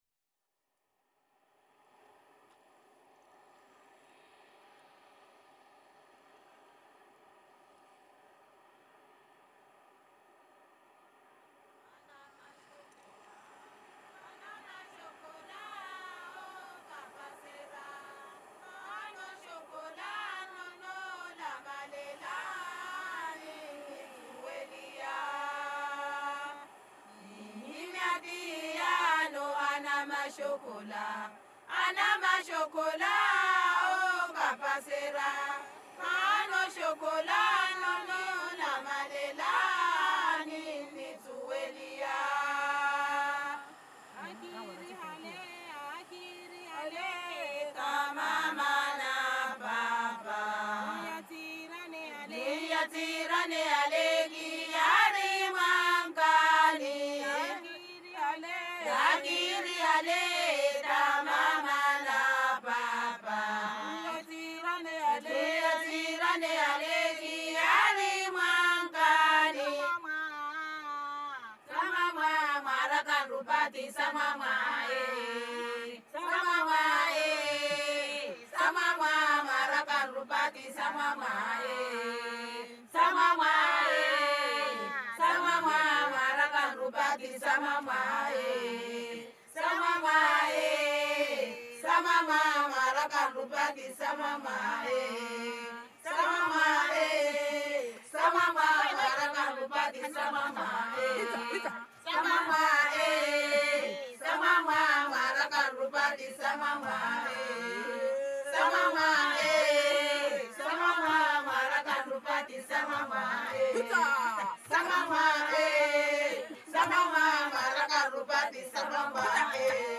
Along Mozambique’s Swahili coast, people chant in a call-and-response rhythm, passing down their wisdom, knowledge of oceanic events like tropical cyclones and warning of the sea’s fragile future.
Inspired by the call-and-response rhythm, recent discoveries and ancient chants from southern east Africa will be weaved through storytelling, evoking a deeper listening, contributing to global Ocean “soundtrack”.
• A first complete version of the soundpiece is available